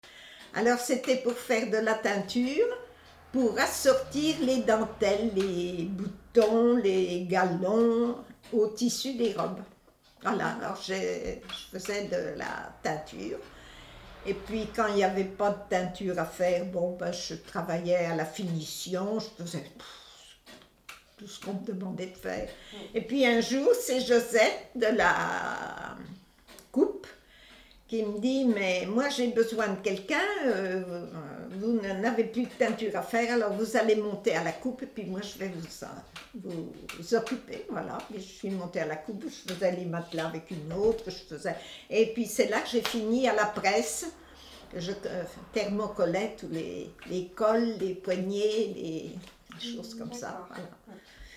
Figure 16 : Extrait vidéo du témoignage d’une ouvrière (thermocolleuse) en confection.